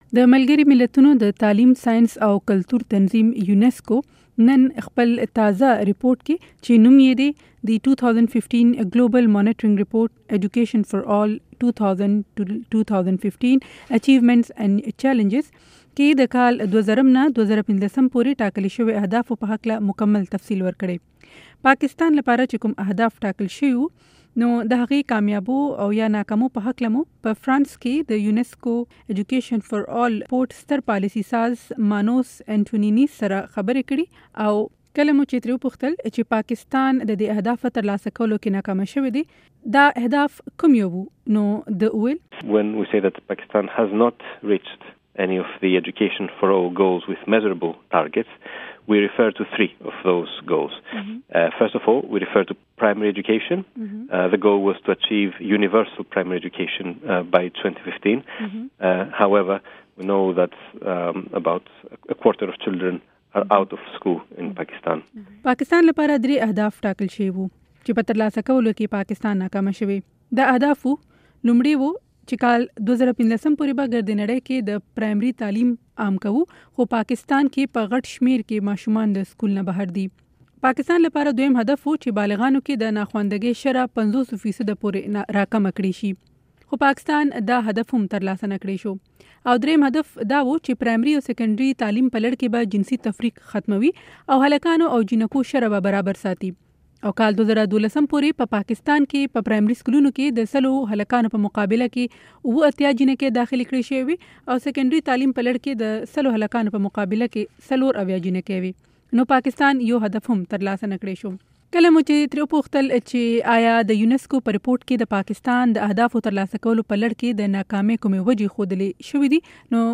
په ټیلې فون مرکه